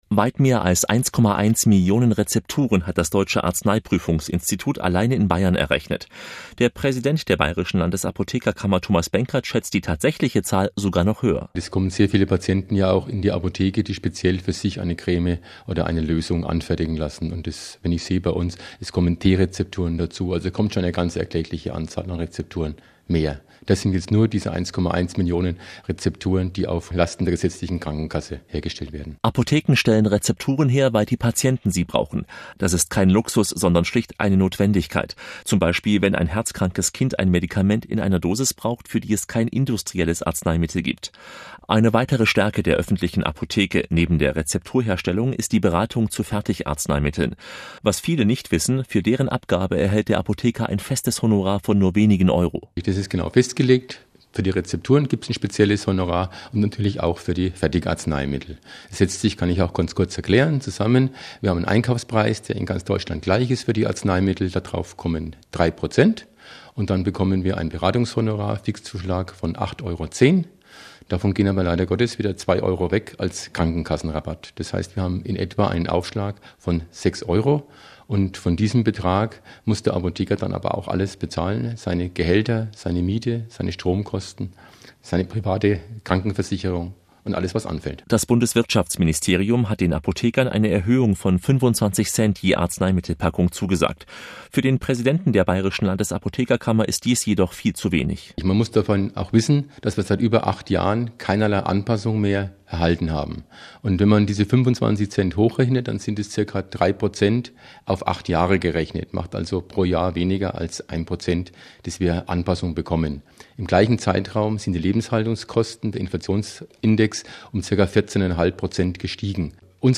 Aktueller Radiobeitrag